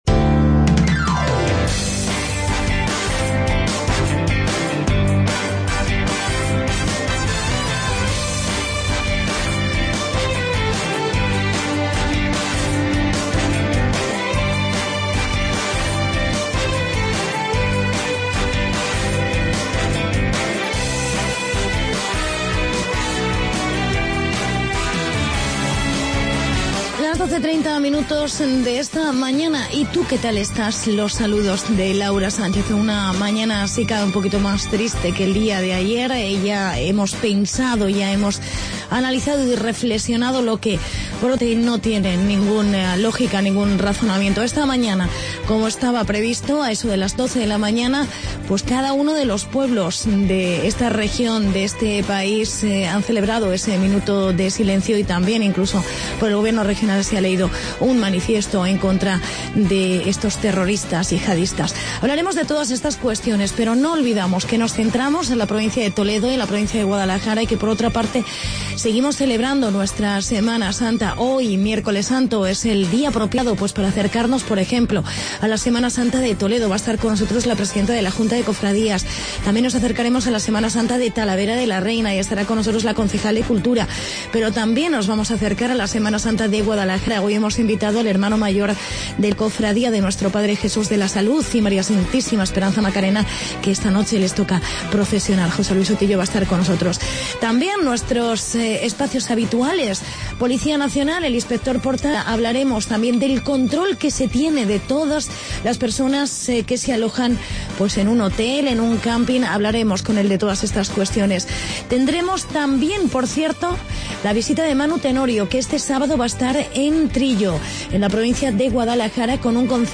Actualidad informativa y entrevista